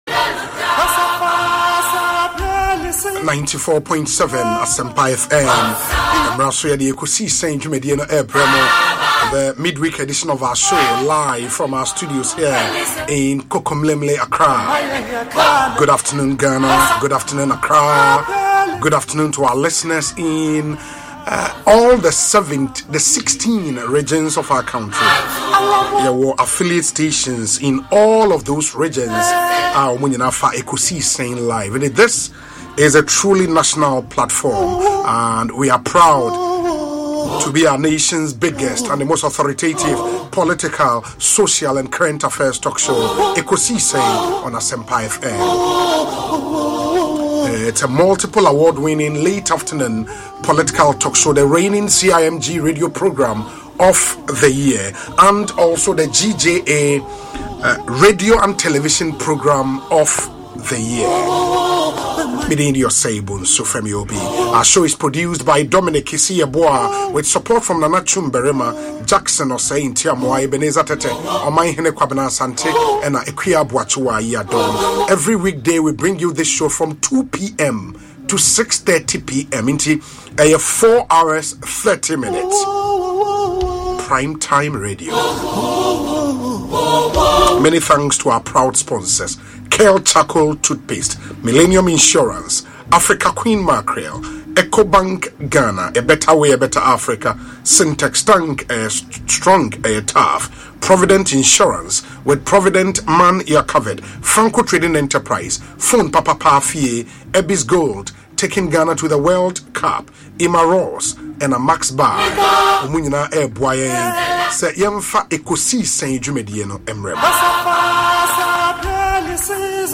A socio-economic political talk show that discusses the major political, social and economic issues in Ghana.